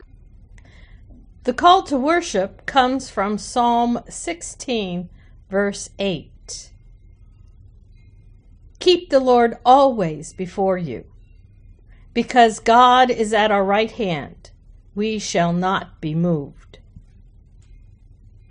Call to Worship: